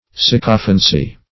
Sycophancy \Syc"o*phan*cy\, n. [Cf. L. sycophantia deceit, Gr.